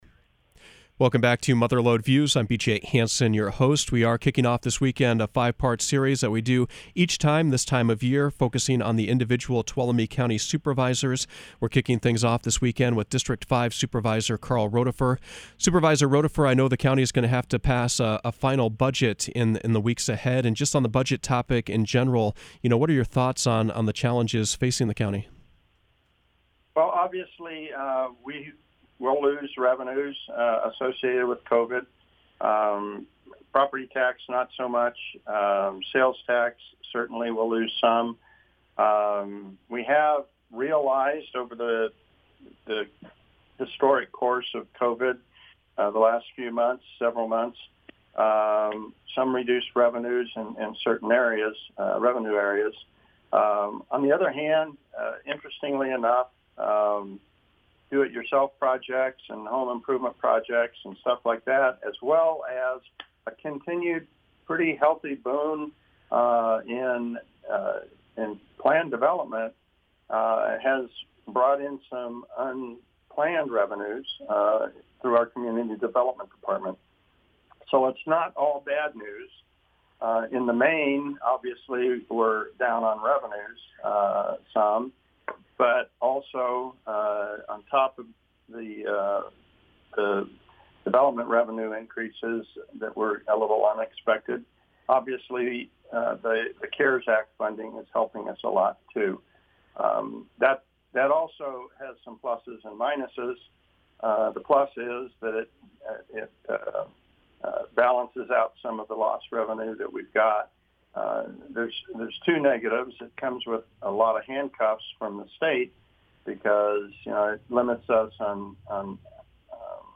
Mother Lode Views began a five-part series focused on the individual Tuolumne County Supervisors. District Five Supervisor Karl Rodefer spoke about issues facing Columbia and Jamestown, and the county as a whole.